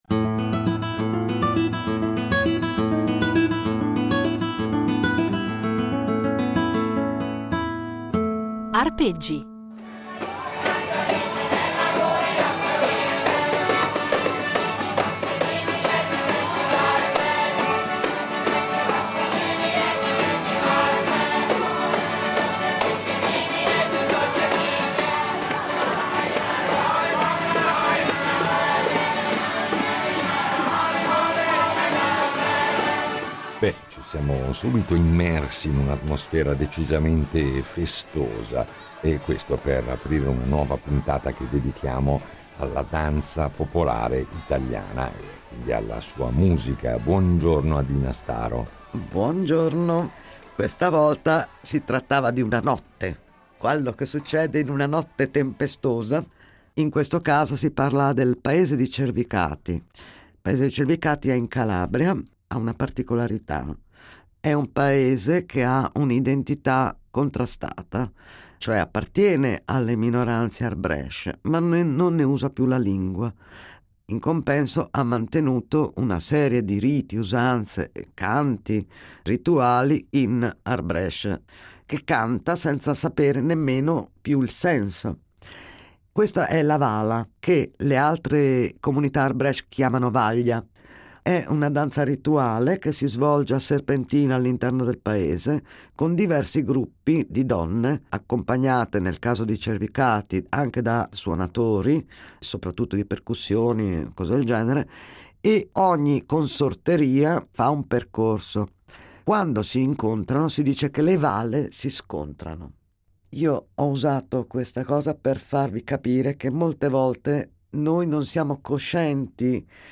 Un itinerario sonoro ricco di materiale inedito, registrato sul campo e negli anni da lei stessa, e illuminato da esempi che ci propone dal vivo, con la sua voce e il suo violino e accompagnata dalle percussioni di